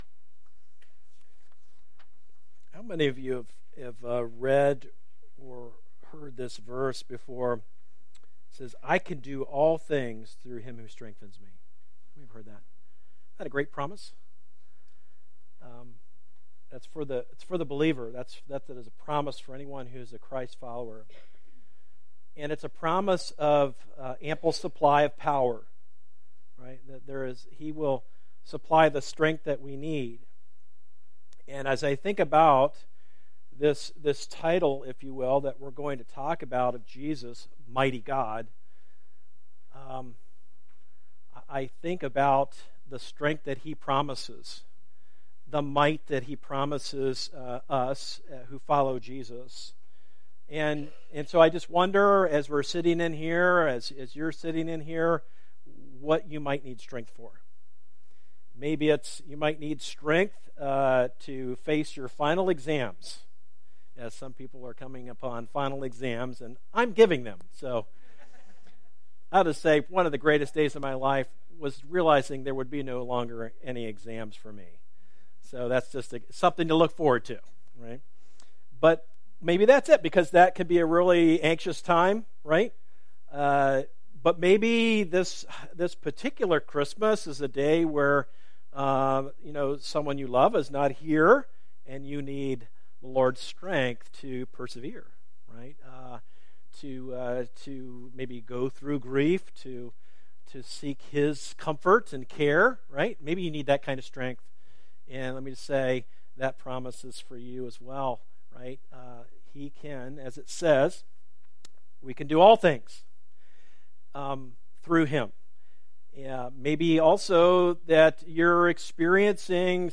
A message from the series "He Will Be Called . . .."